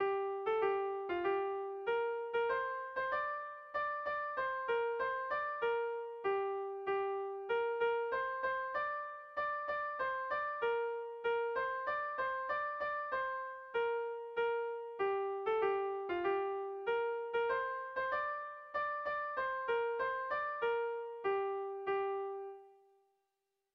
Erlijiozkoa
Seiko handia (hg) / Hiru puntuko handia (ip)
ABA